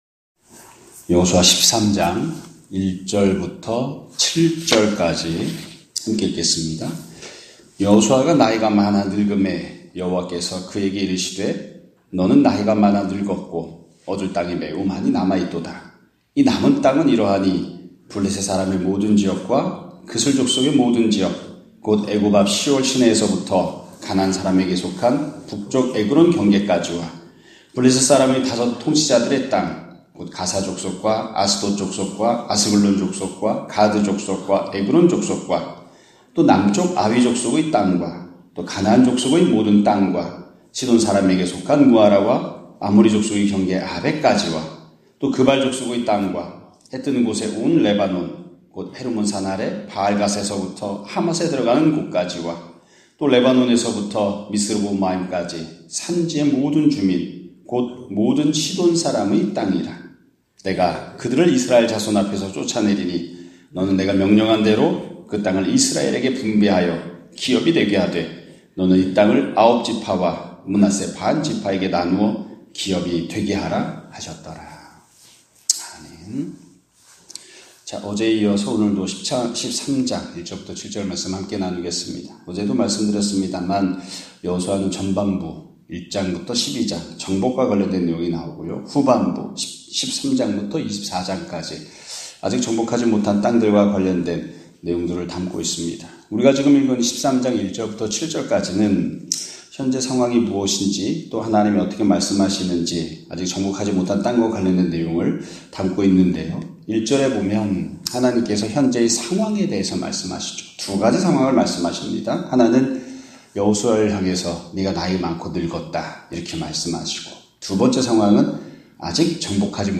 2024년 11월 15일(금요일) <아침예배> 설교입니다.